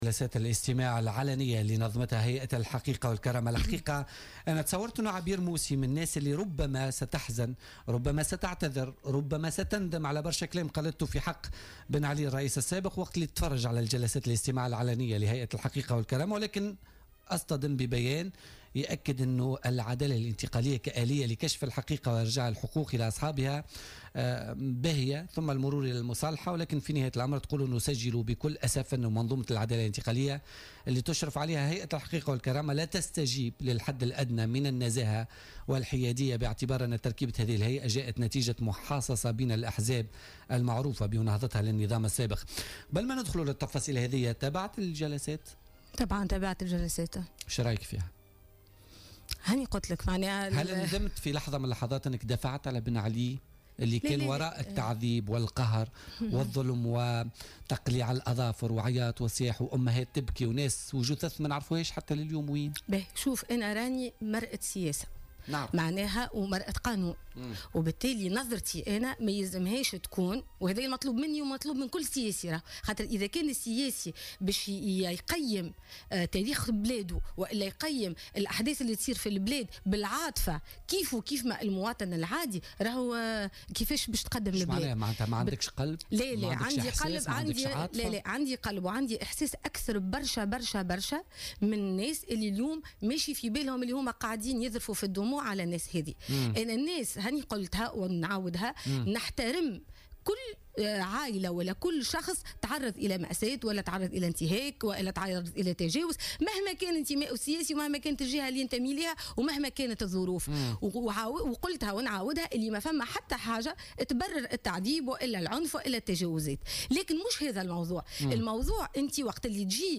قالت عبير موسي ضيفة بولتيكا اليوم الإثنين 28 نوفمبر 2016 أن منظومة العدالة الانتقالية لاتستجيب للحد الأدنى من مقومات العدالة الإنتقالية مشيرة إلى أن برمجة جلسات الإستماع لضحايا التعذيب بالتزامن مع قانون المالية ومنتدى الإستثمار غير بريء على حد قولها.